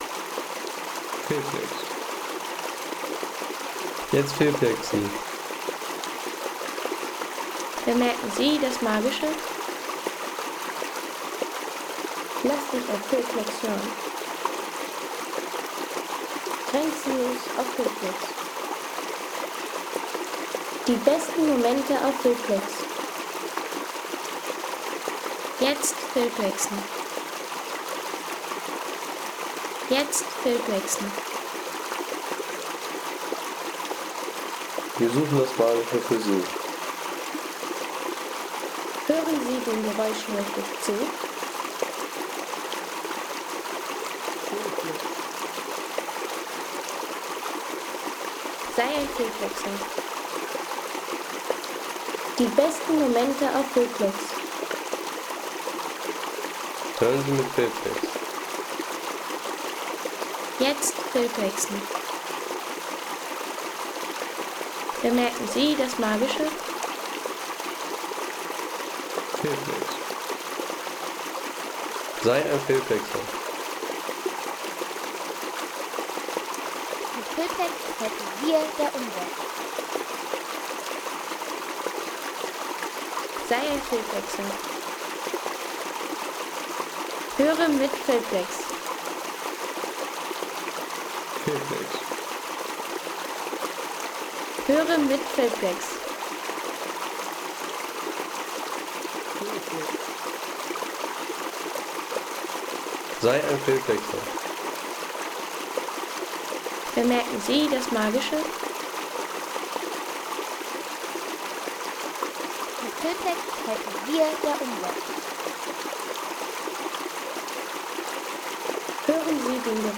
Wasserpark mit Wasserrädern
Wasserpark mit Wasserrädern Home Sounds Natur Wasser Wasserpark mit Wasserrädern Seien Sie der Erste, der dieses Produkt bewertet Artikelnummer: 148 Kategorien: Natur - Wasser Wasserpark mit Wasserrädern Lade Sound....